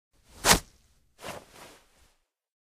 throw.ogg